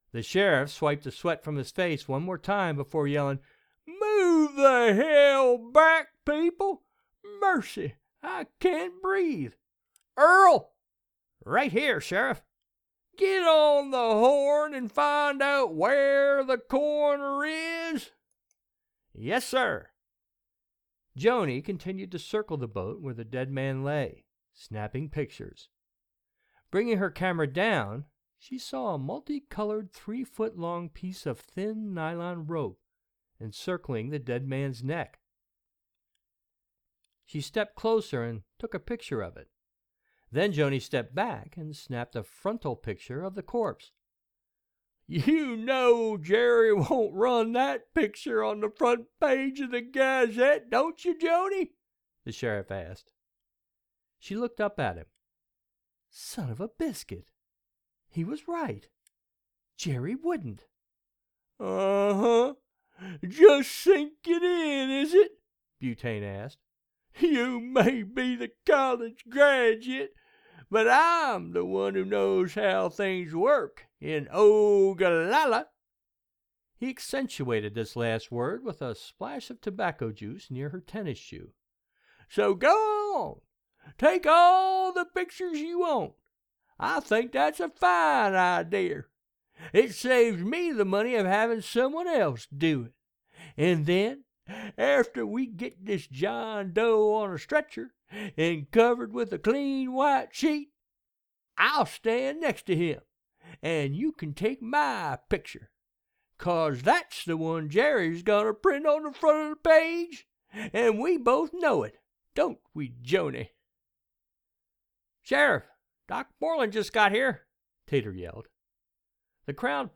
Audiobooks
He also enjoyed performing all the characters while reading them stories.